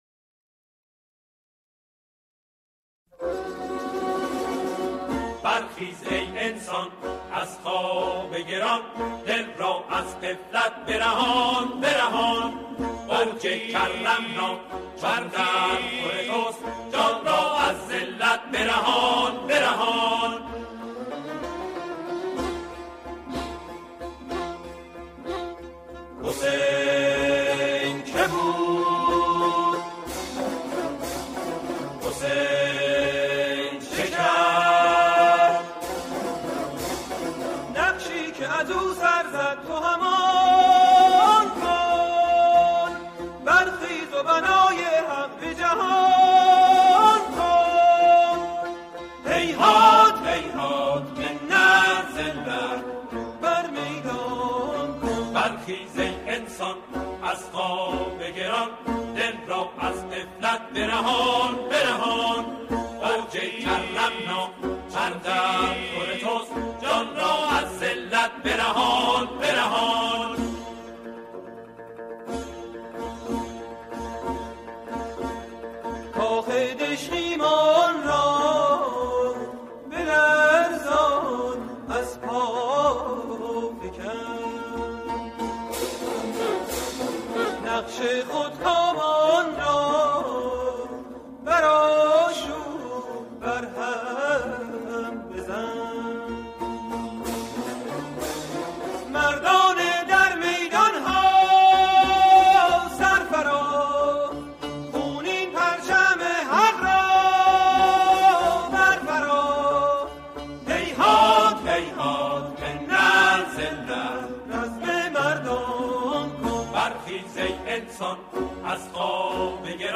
سرودهای امام حسین علیه السلام
همخوانی